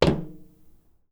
footstep SFX
metal11.wav